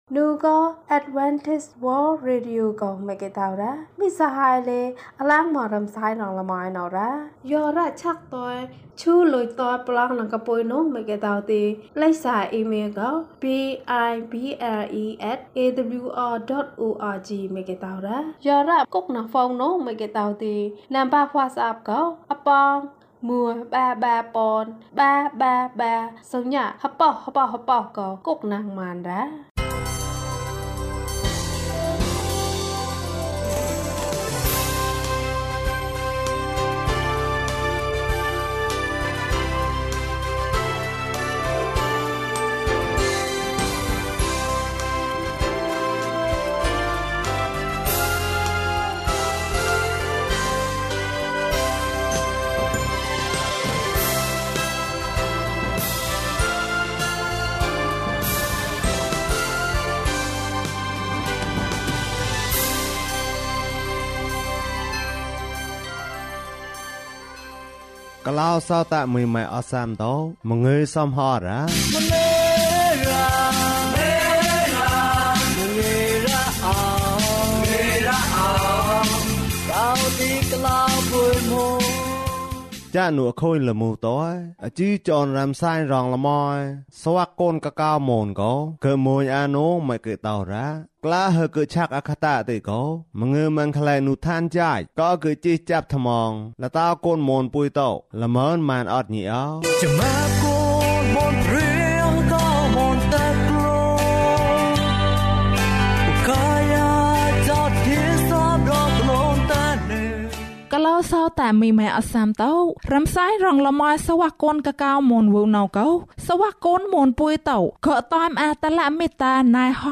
ခရစ်တော်ကို ယုံကြည်ပါ။ ၀၁ ကျန်းမာခြင်းအကြောင်းအရာ။ ဓမ္မသီချင်း။ တရား‌ဒေသနာ။